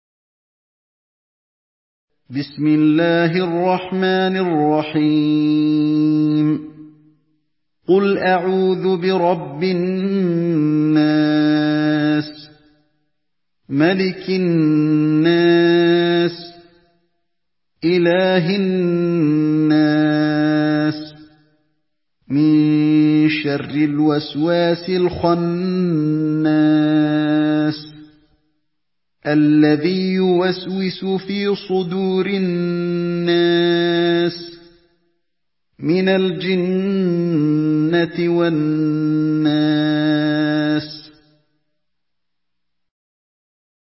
Surah الناس MP3 by علي الحذيفي in حفص عن عاصم narration.
مرتل